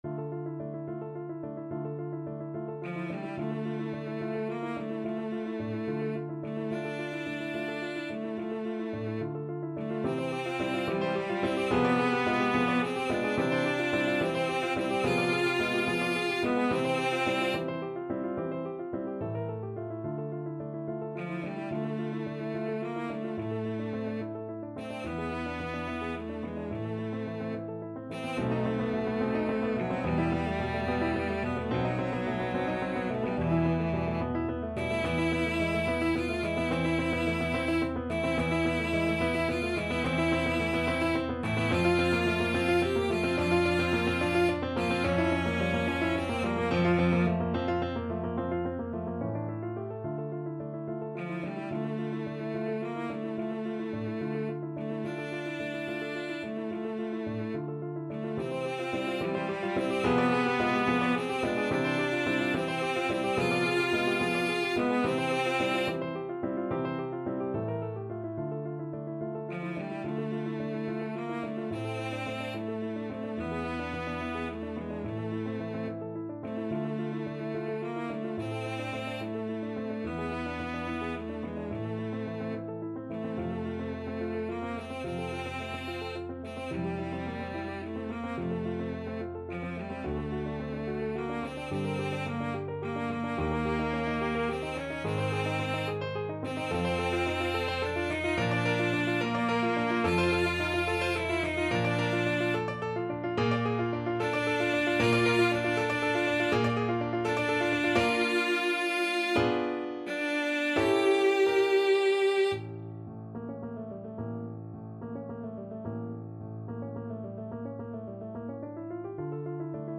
Classical Cello